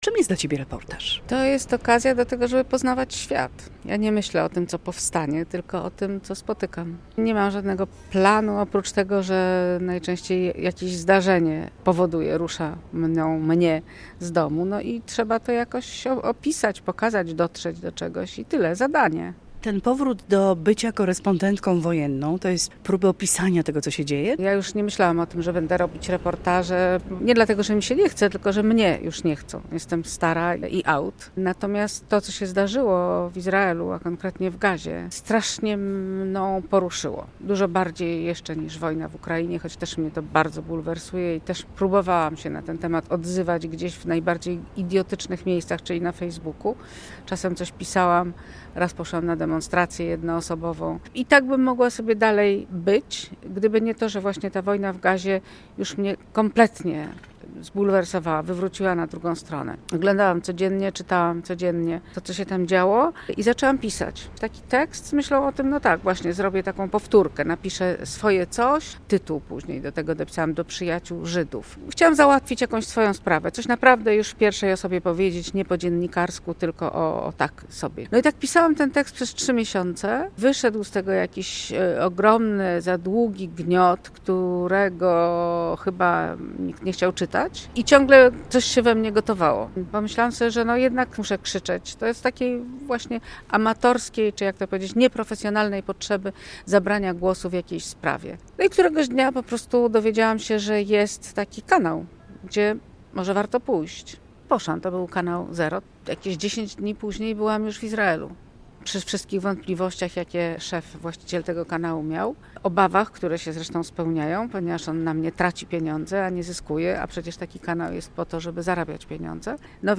Maria Wiernikowska opowiedziała o swoich doświadczeniach z reportażem na Warsztatach Reportażu Radiowego w Tykocinie